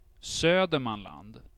Södermanland (Swedish pronunciation: [ˈsø̂ːdɛrmanˌland] or [ˈsø̌ː-]